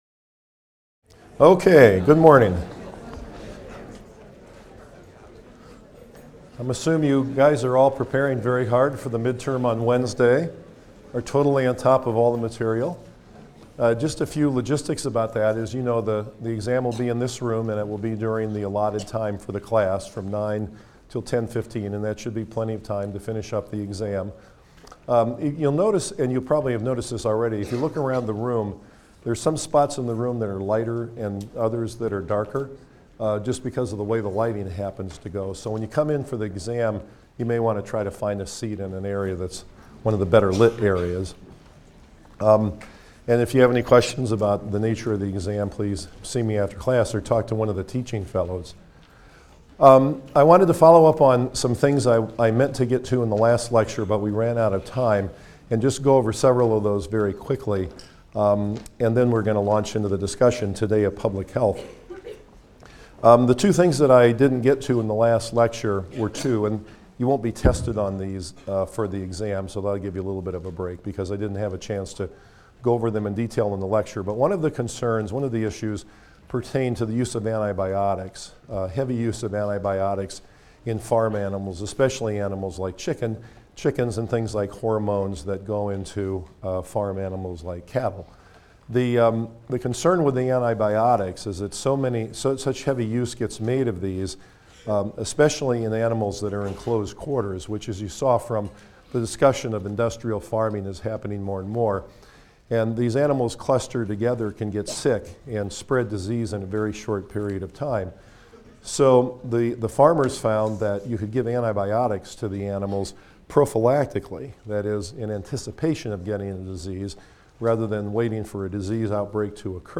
PSYC 123 - Lecture 12 - Public Health vs. Medical Models in Nutrition Change: Saving Lives One or a Million at a Time | Open Yale Courses